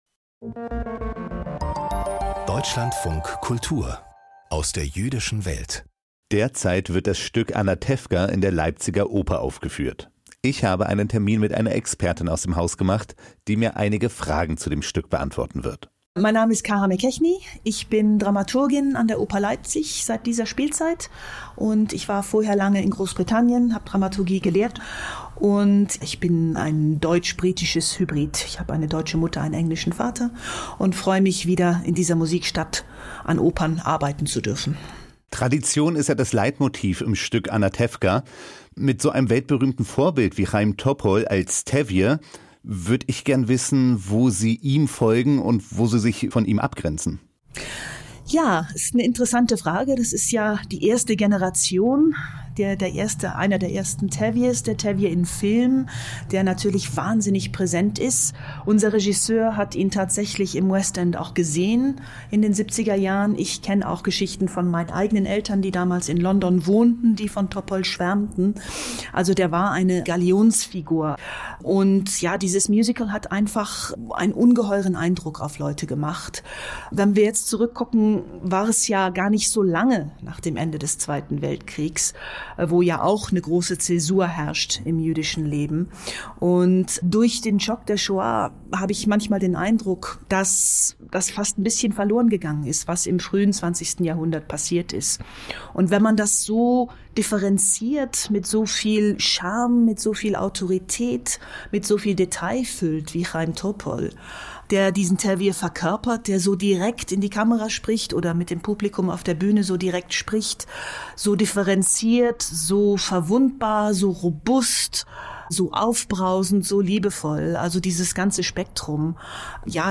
Ob kitschiges oder aktuelles Musical - Gespräch über Anatevka an der Oper Leipzig